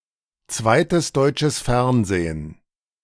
ZDF (German: [ˌtsɛt.deːˈʔɛf] ), short for Zweites Deutsches Fernsehen[1] (German: [ˈtsvaɪtəs ˈdɔʏtʃəs ˈfɛʁnzeːn]
De-Zweites_Deutsches_Fernsehen.ogg.mp3